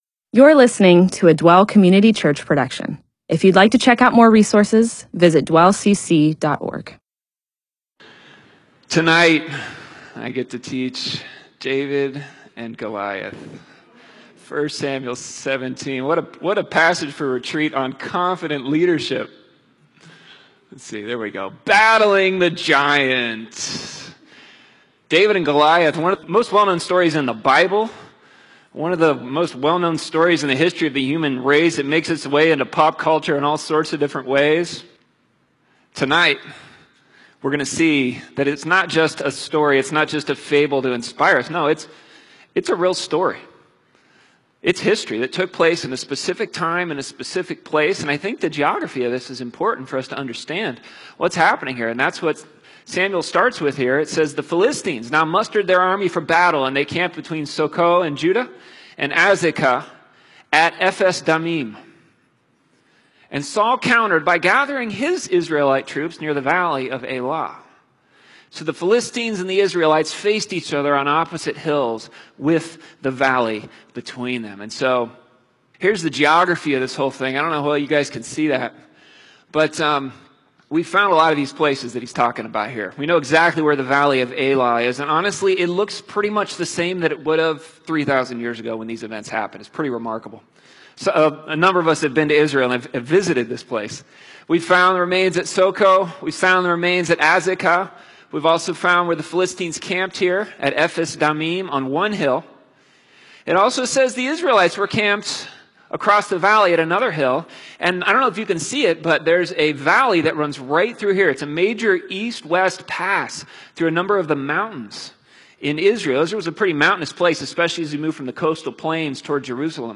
MP4/M4A audio recording of a Bible teaching/sermon/presentation about 1 Samuel 17:1-52.